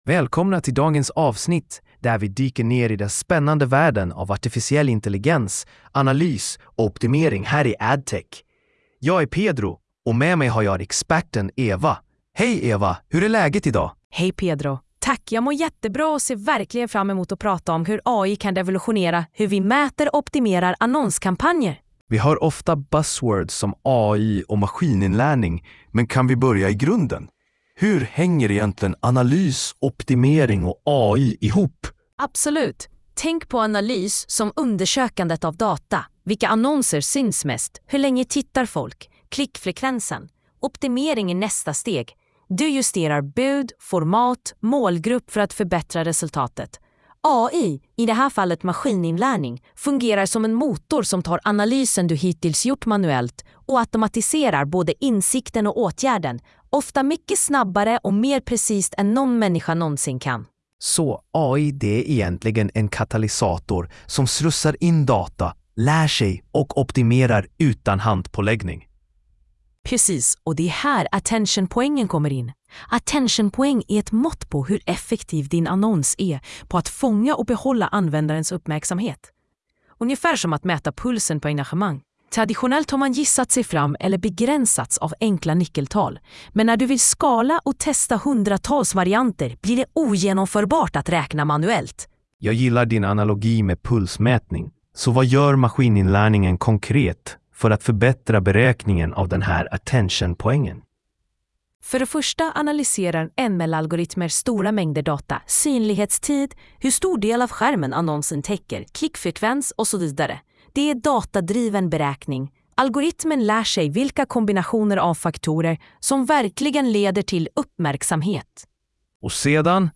Lyssna på avsnittet genom en AI-genererad podcast: